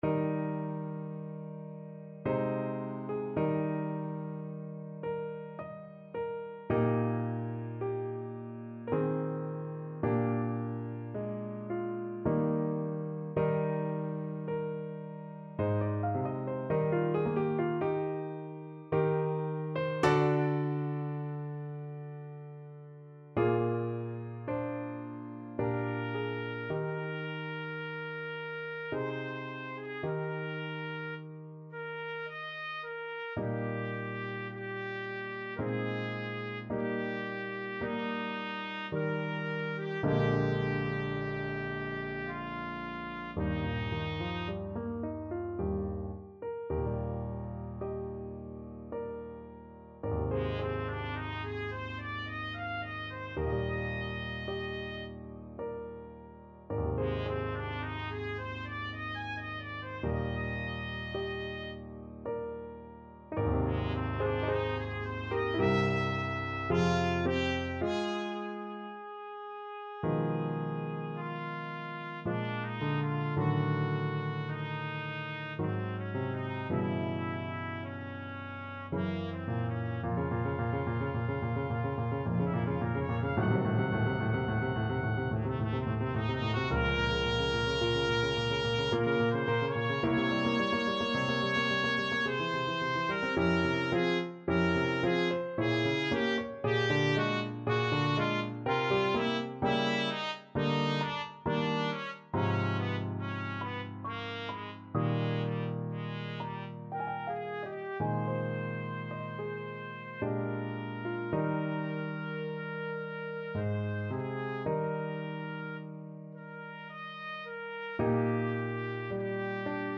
Classical Dvořák, Antonín Cello Concerto, Op.104, 2nd Movement Main Theme Trumpet version
Trumpet
3/4 (View more 3/4 Music)
Eb major (Sounding Pitch) F major (Trumpet in Bb) (View more Eb major Music for Trumpet )
Adagio ma non troppo =108
Classical (View more Classical Trumpet Music)